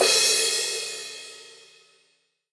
S_crash1_1.wav